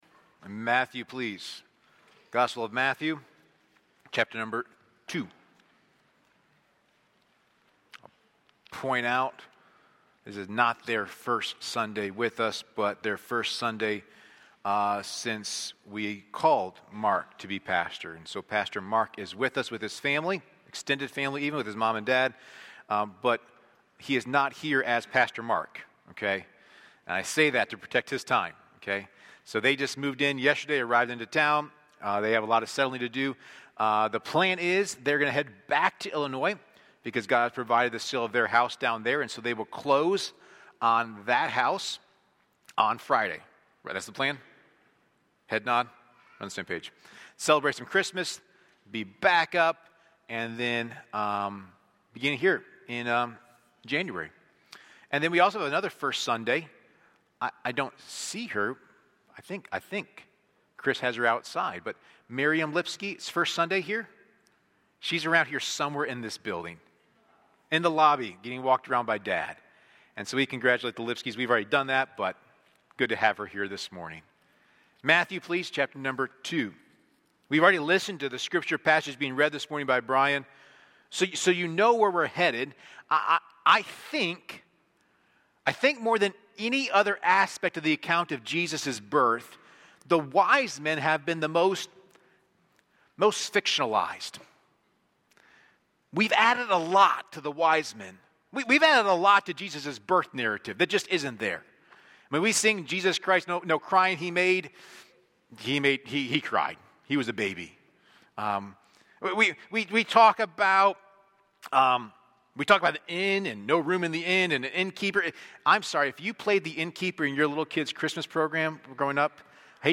A sermon from the series "Individual Sermons."